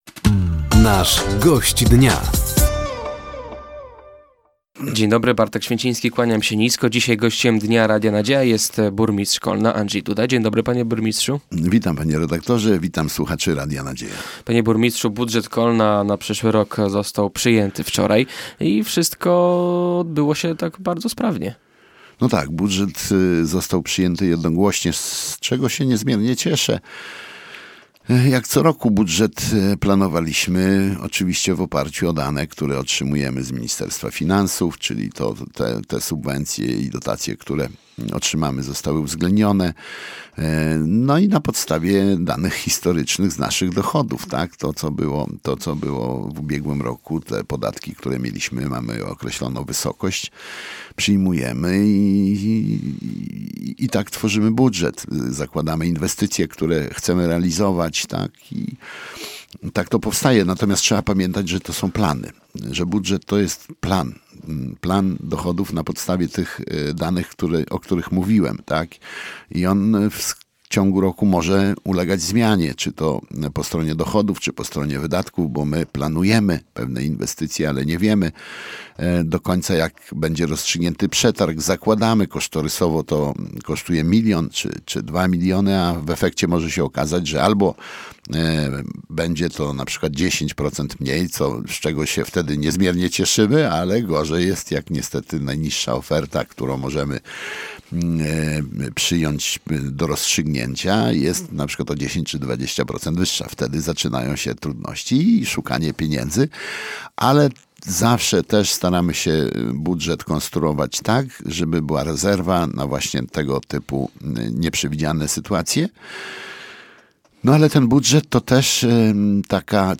Gościem Dnia Radia Nadzieja był Andrzej Duda, burmistrz Kolna. Tematem rozmowy był przyjęty budżet miasta na przyszły rok.